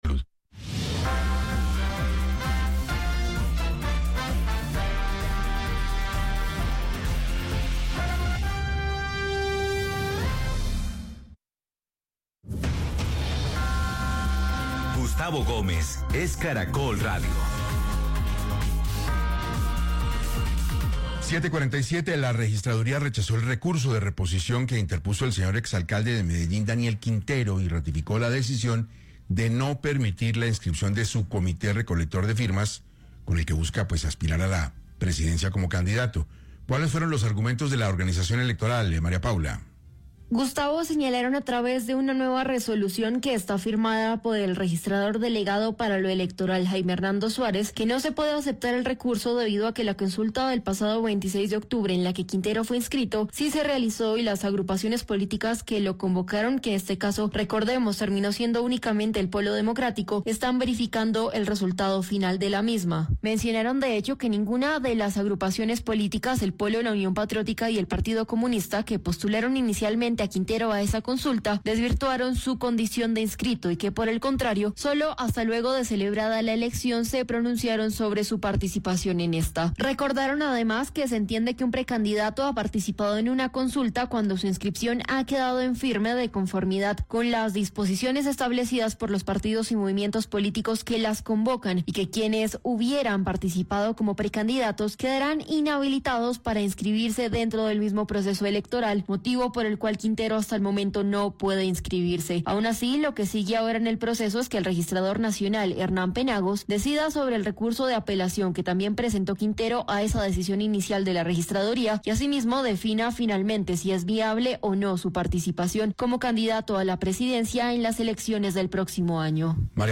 Con estas palabras, el exalcalde de Medellín Daniel Quintero se refirió en 6AM de Caracol Radio a la decisión del Registrador Nacional que impidió su inscripción como candidato a la Gobernación de Antioquia, medida que –según él– también afectará al senador Iván Cepeda.